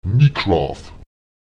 Lautsprecher 36 [Èmik¨aùf] 30